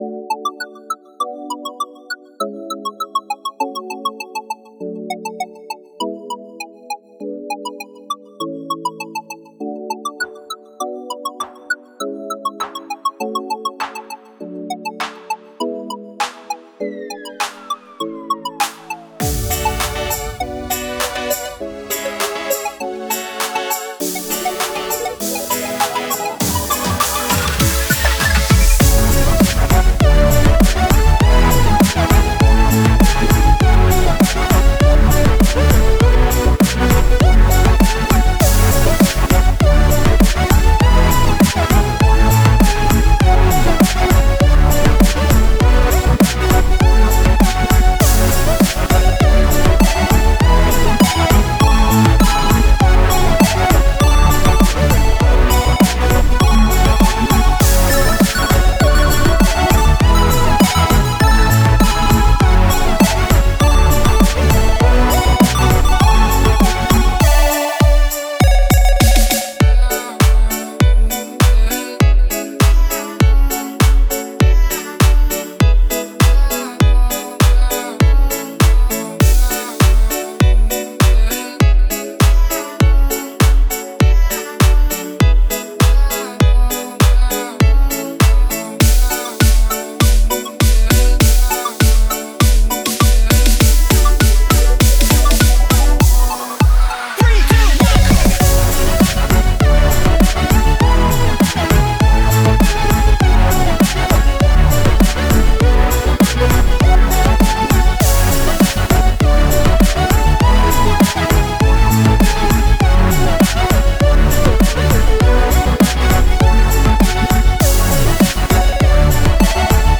いろいろと実験的な作品です。